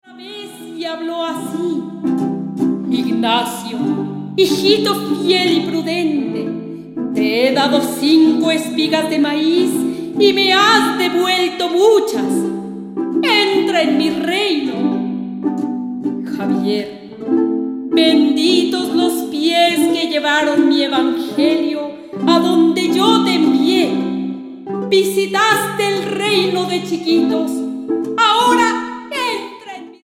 Aria
Dúo
soprano, flauta, percusiones
violín, violín piccolo
guitarra barroca, laúd, mandolina, charango, viola de gamba